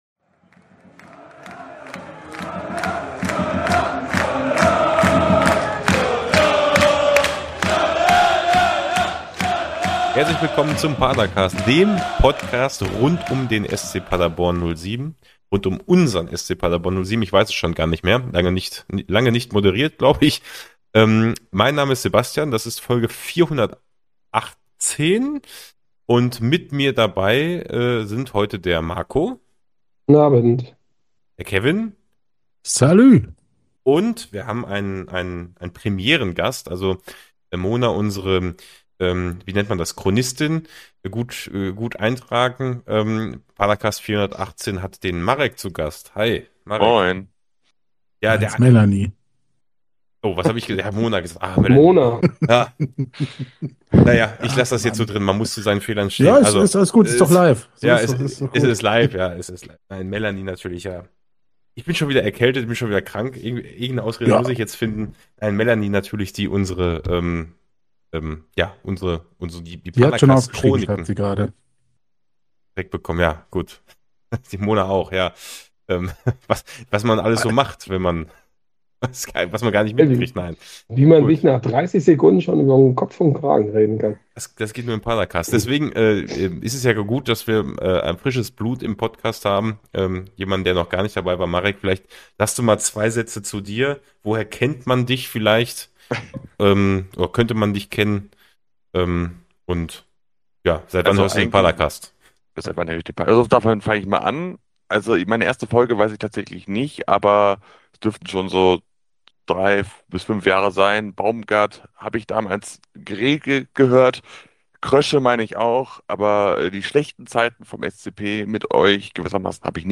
Nach einem nervenaufreibenden Auftakt mit einem frühen Gegentreffer wendet sich das Blatt: Der SCP07 profitiert von einer Überzahlsituation und wir agieren fast 80 Minuten lang mit einem Mann mehr auf dem Platz. Das Ganze analysieren wir seit langer Zeit mal wieder zu viert!